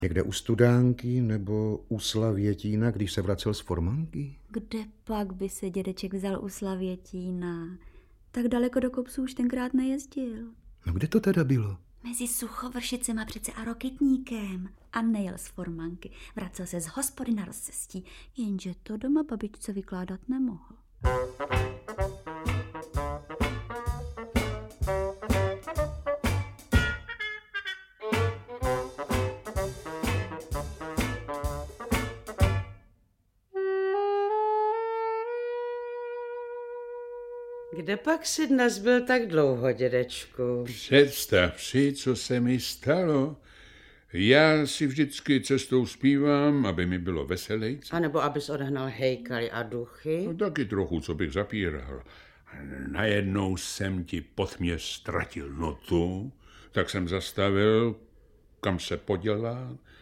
Audiobook
Read: Věra Kubánková